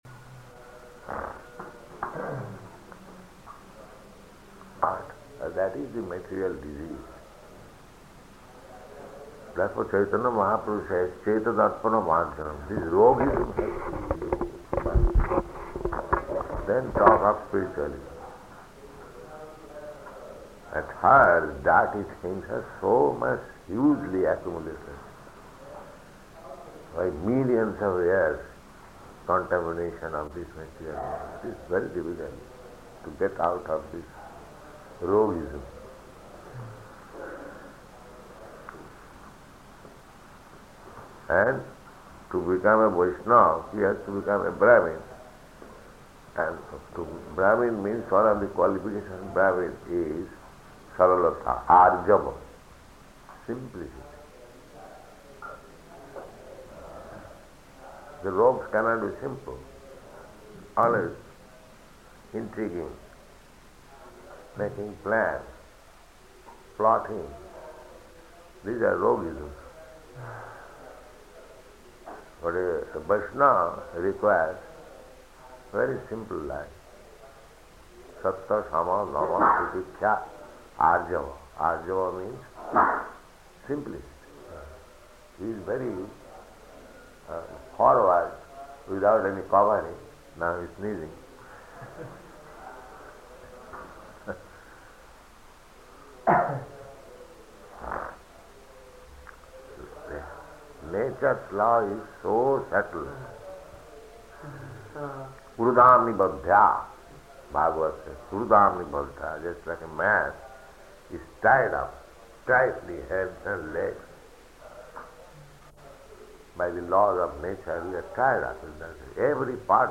Room Conversation
Location: Surat